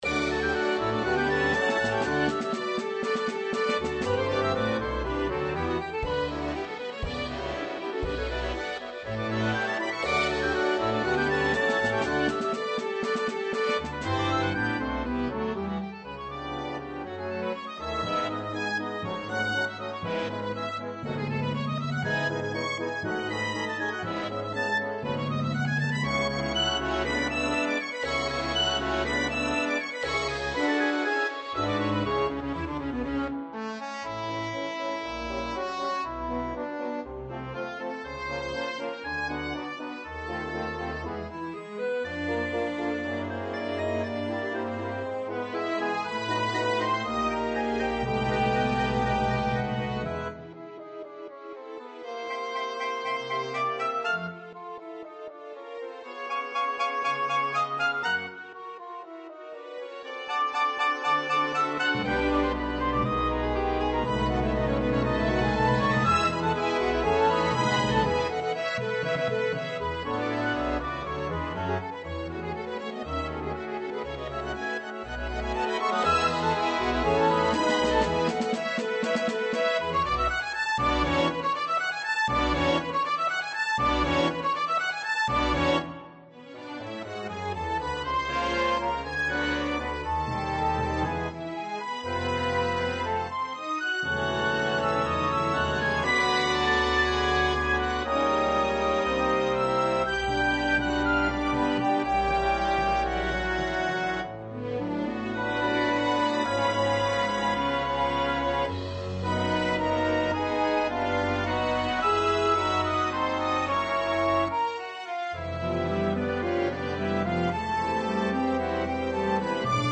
for orchestra
Strings (Violin 1, Violin 2, Viola, Cello, Bass)